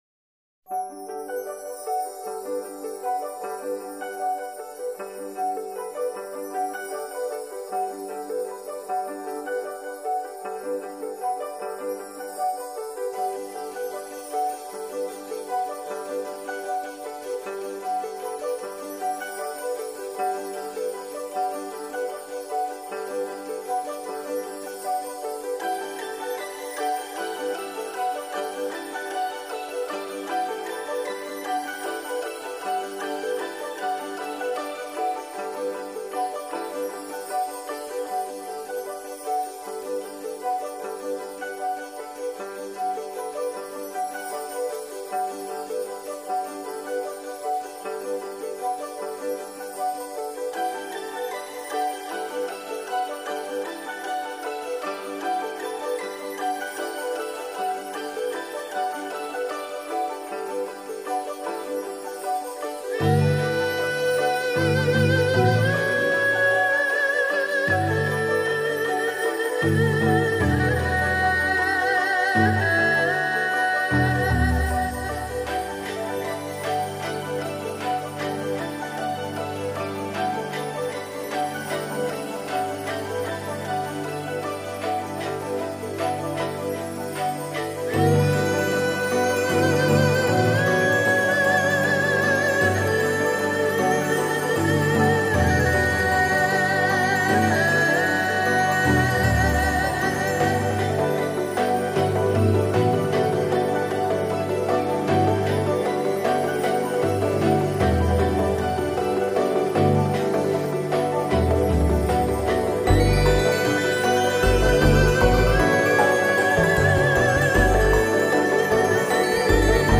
多了一丝空灵和荡气回肠的柔情